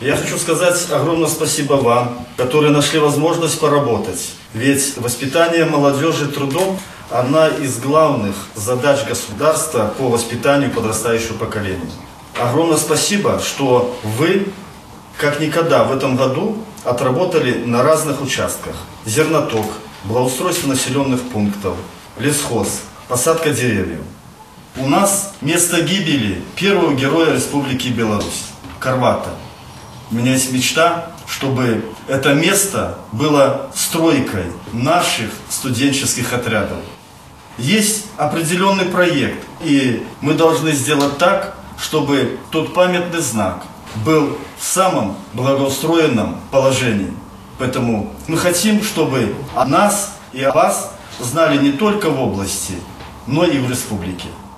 Перед собравшимися выступил заместитель председателя Барановичского райисполкома Михаил Борисевич. Он рассказал о значимости   молодёжных трудовых отрядов.